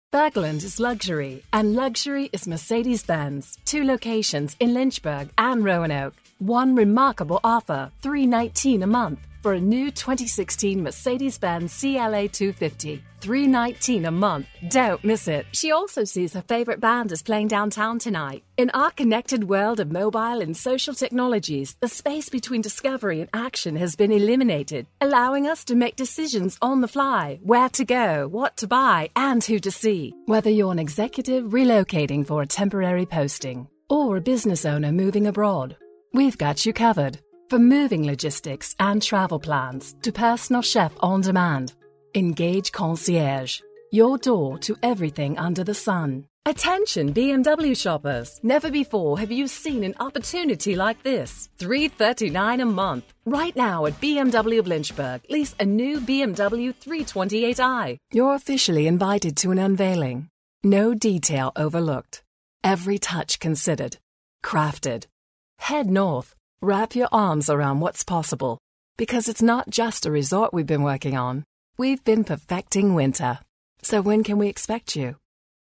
Demo
Adult, Young Adult
Has Own Studio
british english
explainer video